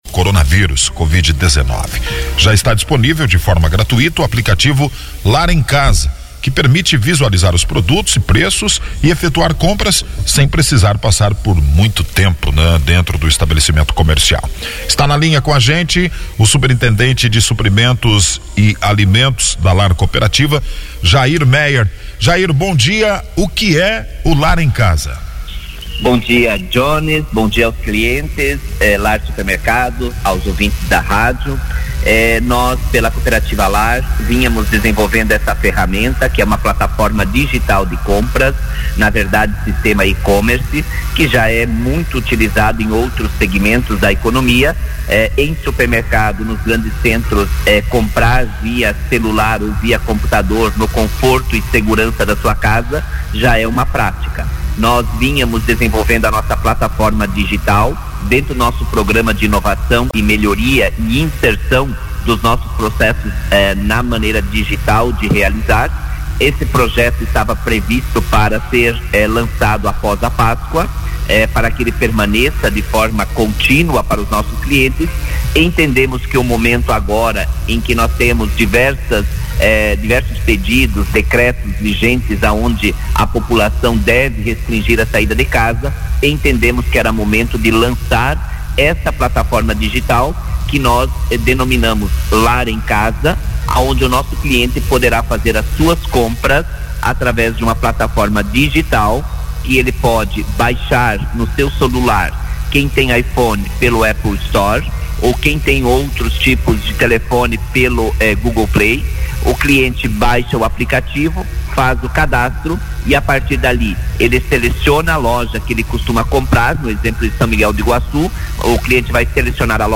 Em entrevista ao “Jornal da Manhã” pela Rádio Jornal AM 1400